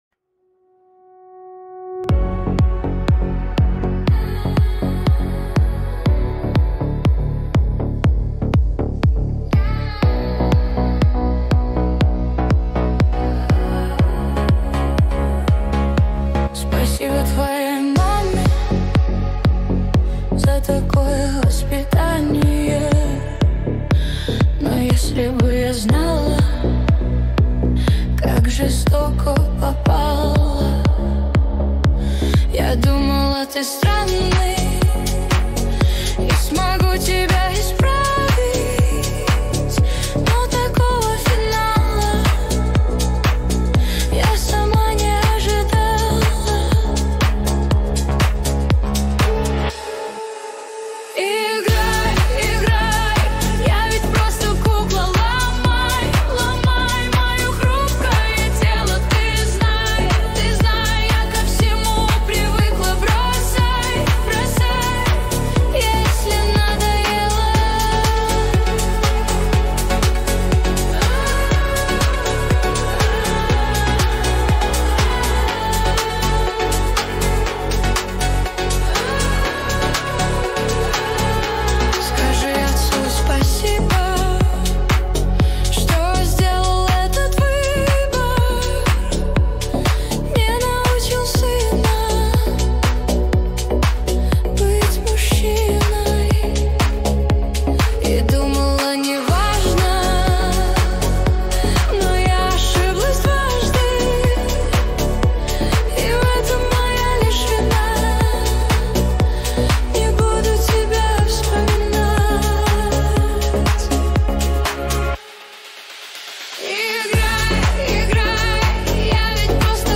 Качество: 320 kbps, stereo
Демо, Поп музыка